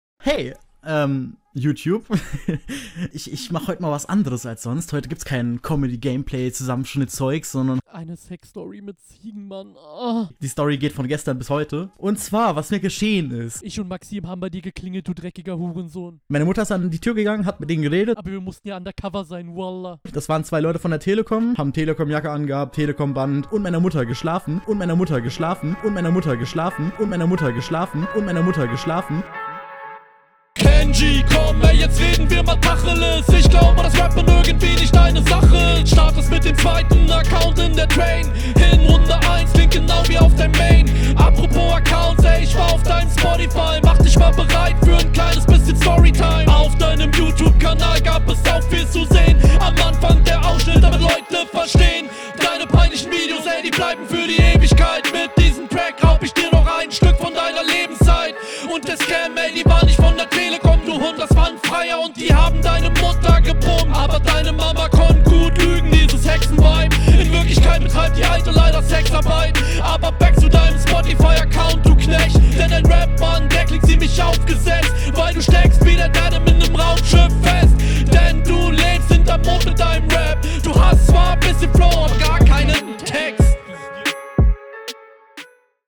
Flow:geht auch hier gut nach vorne sowas mag ich, hättest vielleicht was variation noch reinbringen …
Flow: Variation ist nice nur ich hab das gefühl ein zwei flowfehler bei denn zwei …